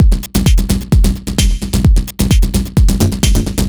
Swine1 130bpm.wav